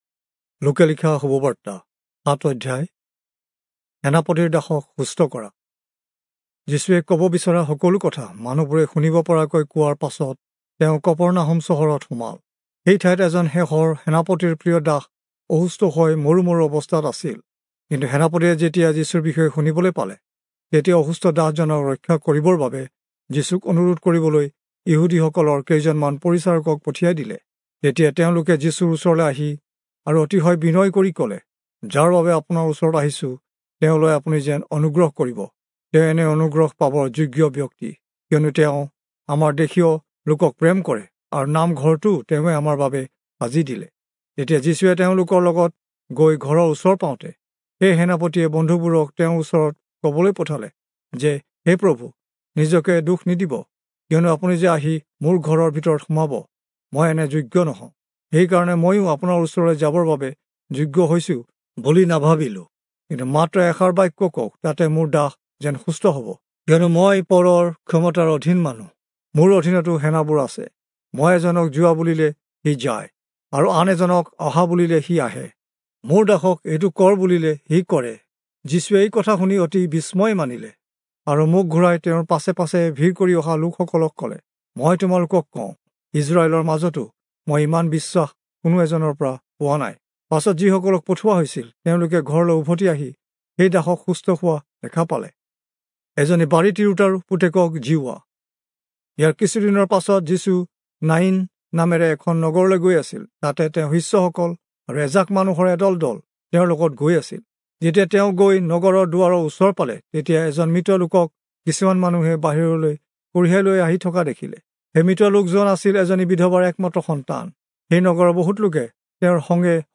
Assamese Audio Bible - Luke 18 in Ocvta bible version